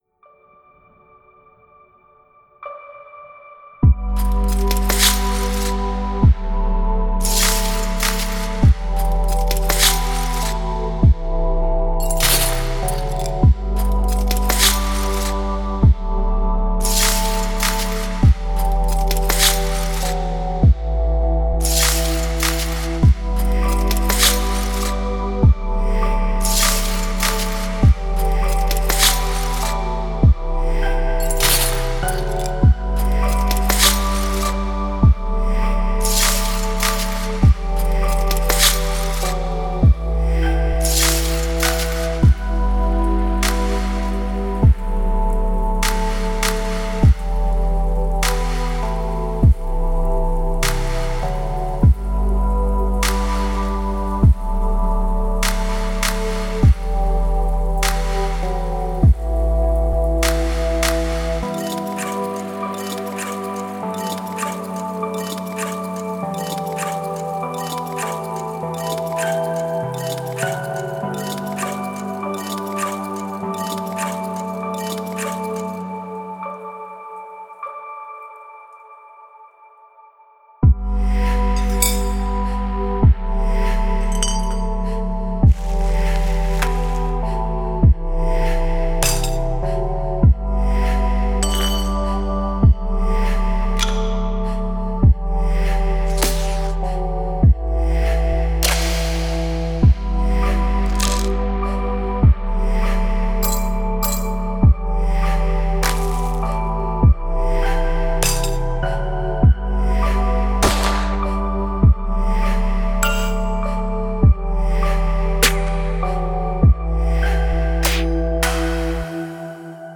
Chill Out / Lounge Multi-genre
- 64 Straight Claps & Snares
- 20 Atmospheric Textures